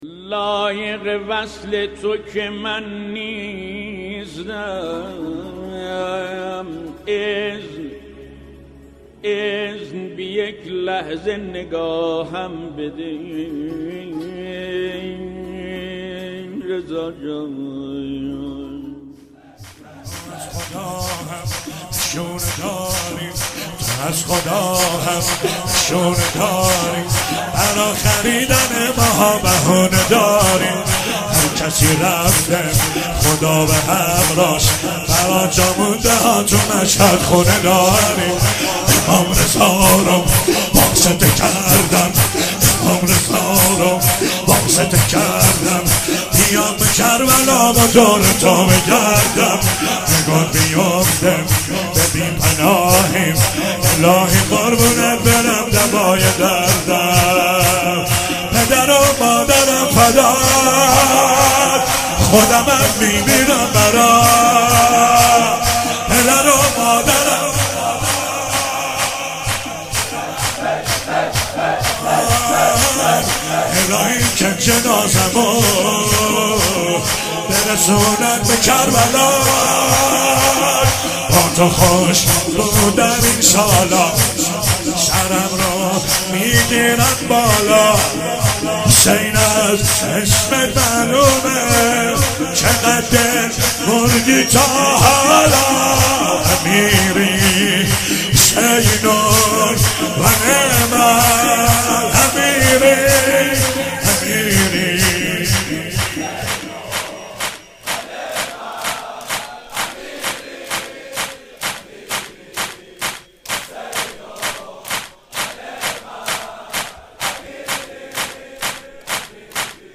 شهادت امام رضا علیه السلام 96 - شور - تو از خدا هم نشونه داری